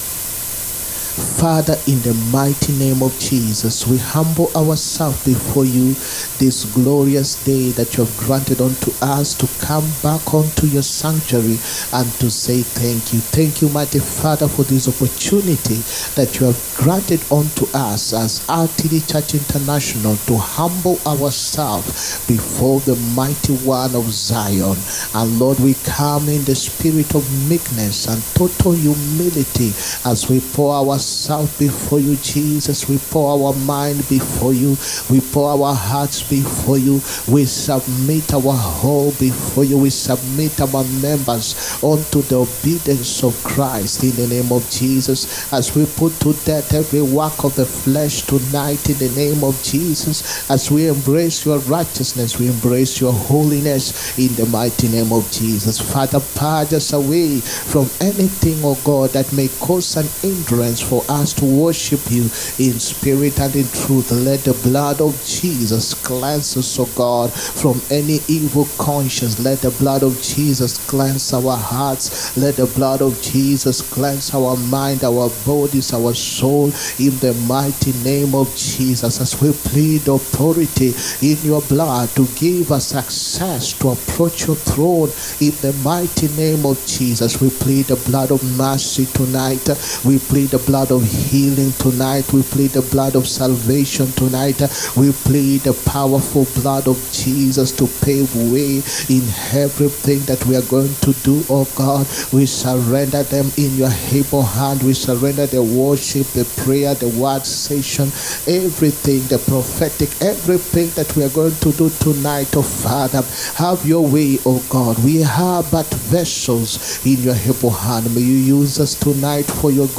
HEALING, PROPHETIC AND DELIVERANCE SERVICE. 29TH NOVEMBER 2024.